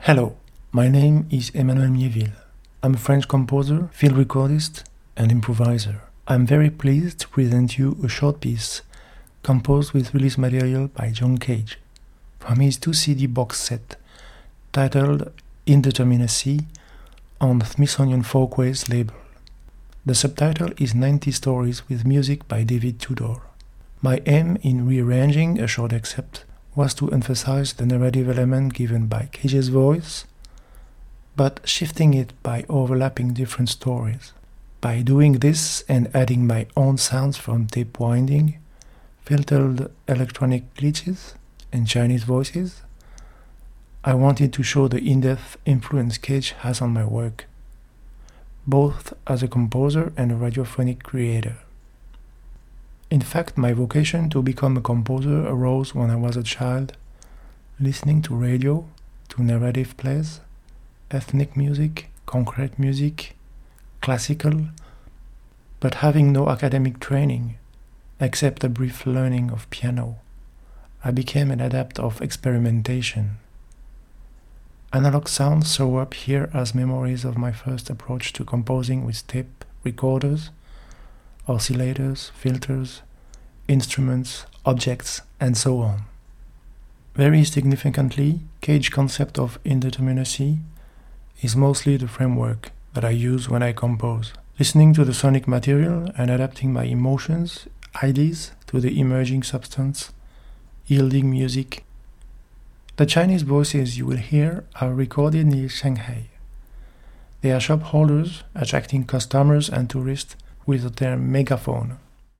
Transmission Arts & Experimental Sounds